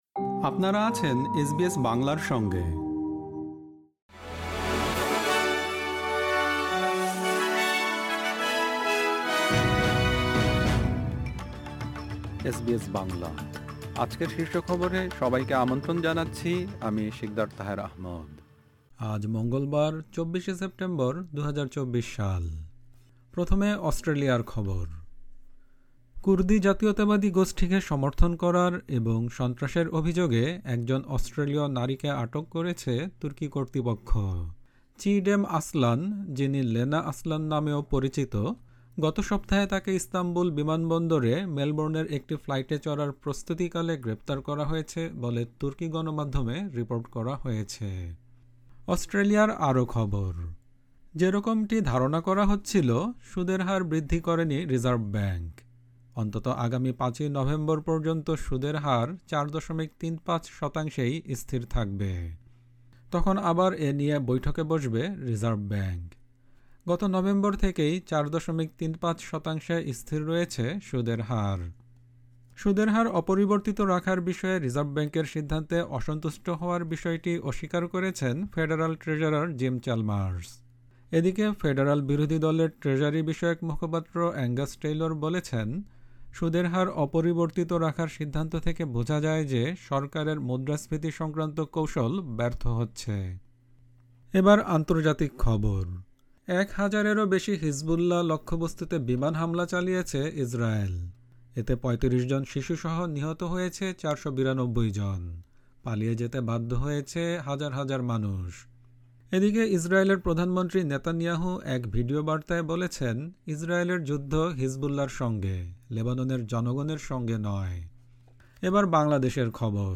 এসবিএস বাংলা শীর্ষ খবর: ২৪ সেপ্টেম্বর, ২০২৪